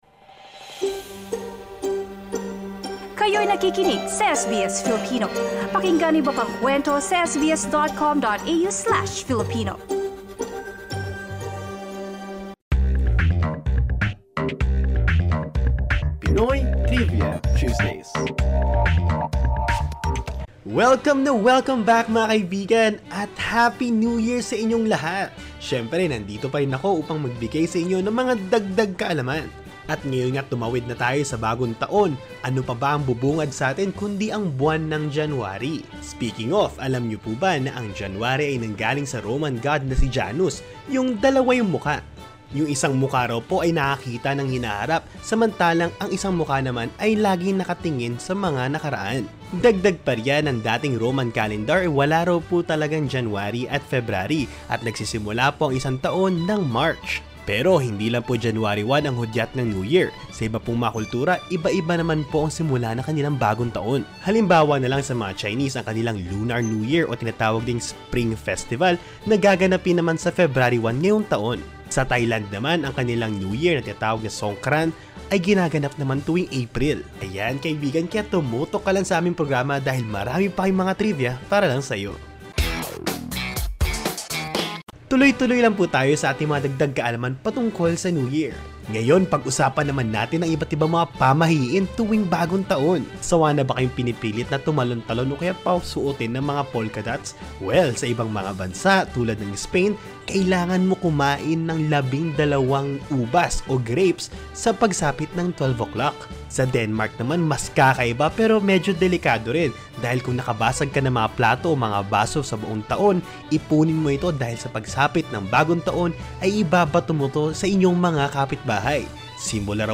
Pinoy Trivia Tuesdays is a weekly segment aired on the SBS Filipino radio program featuring interesting facts and tidbits about anything and everything.